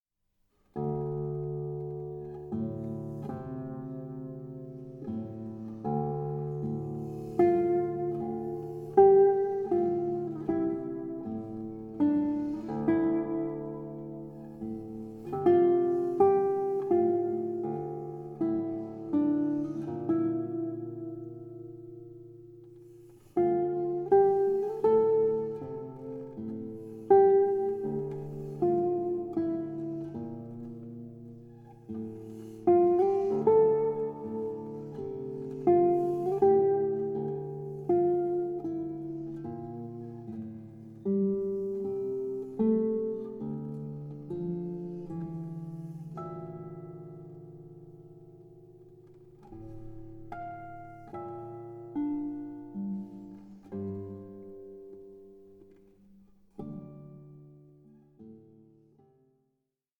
for guitar